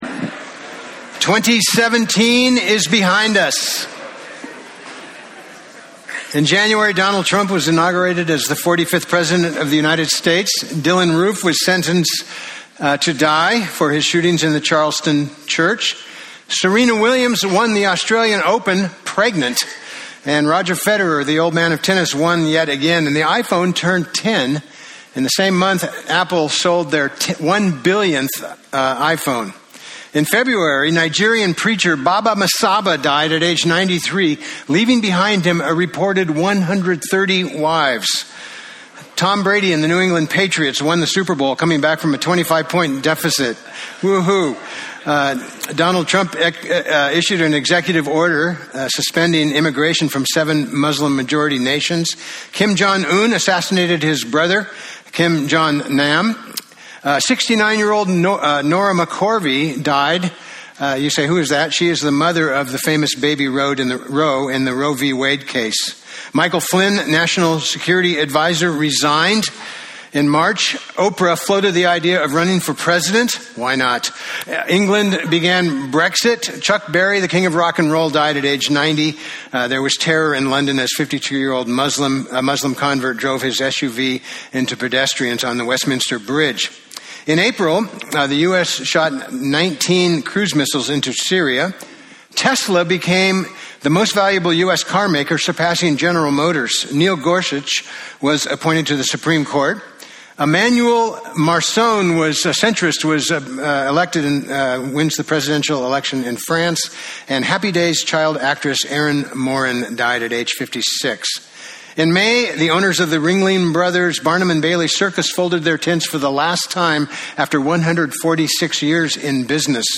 Preacher
Psalm 145 Service Type: Sunday Topics